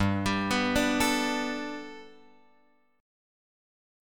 Gadd9 chord {3 x 5 4 3 5} chord